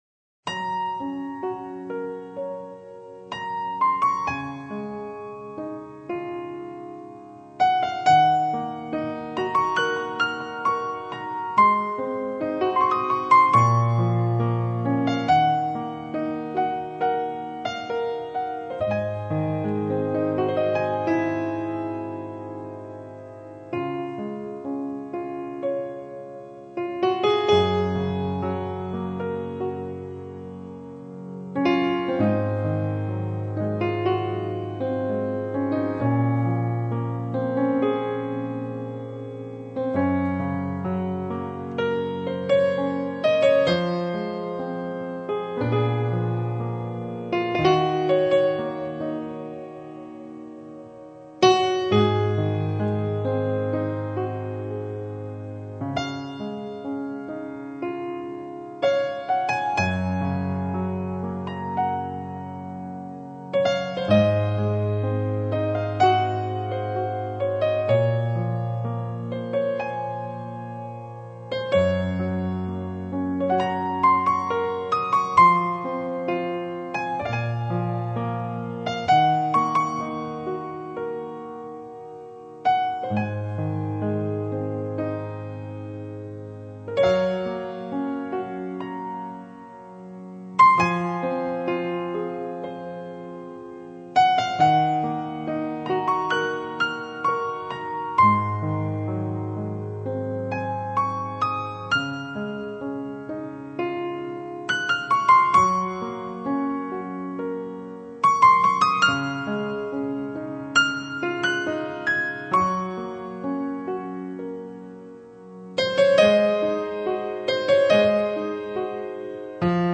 曲风缓慢恬静，带着丝丝淡淡的忧伤